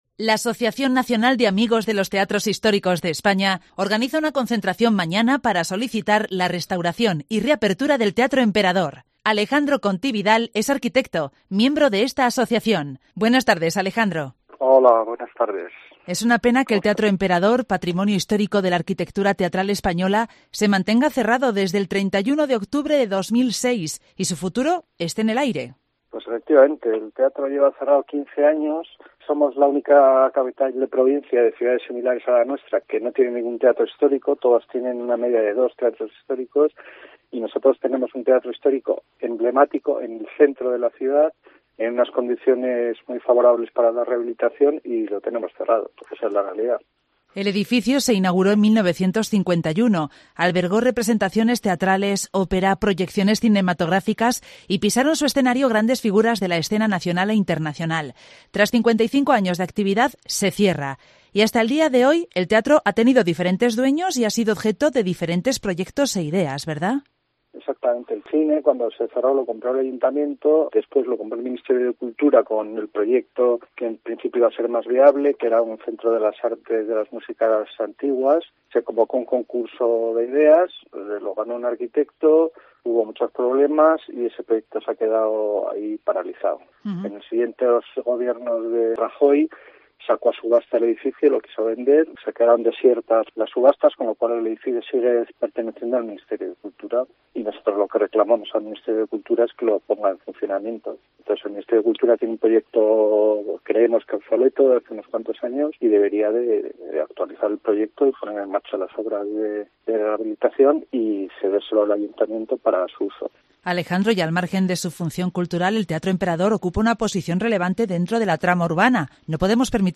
arquitecto, miembro de la asociación